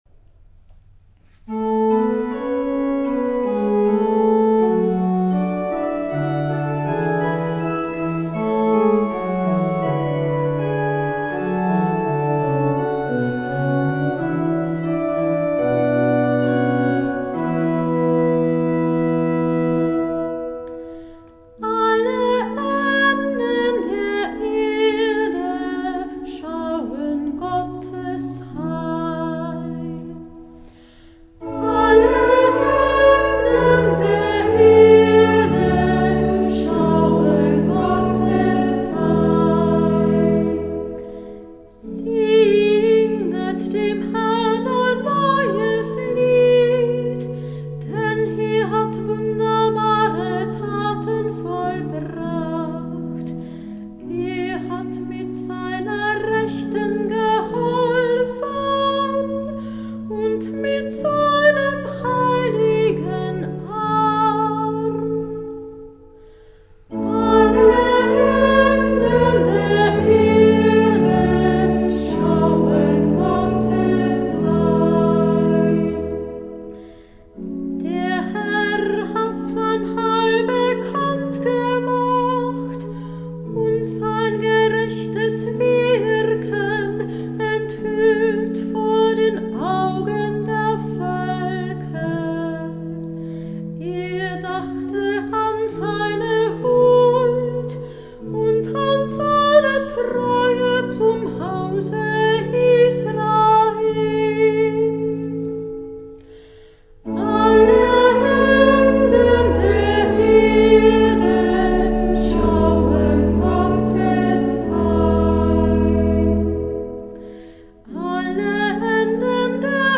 Kantor
Gurker Psalter Klavier